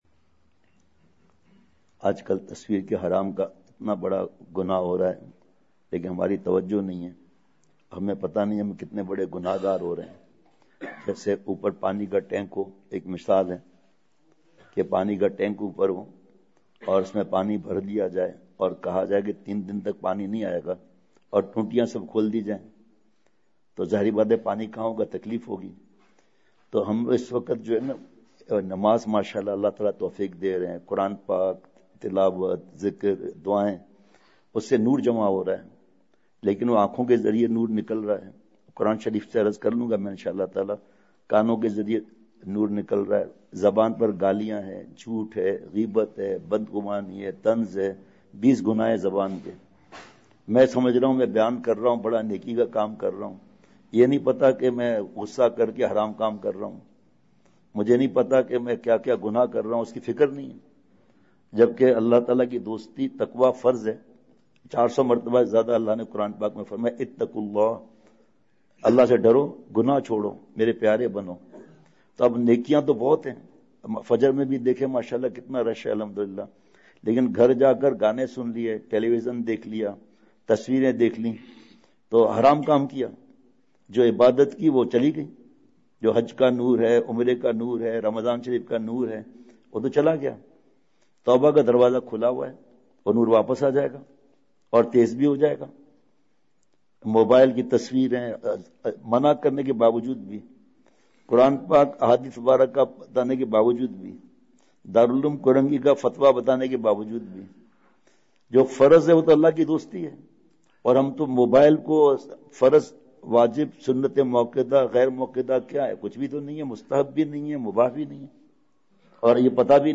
*بمقام:*ابوبکر مسجد سول لائن خانیوال
*نمبر(22):بیان*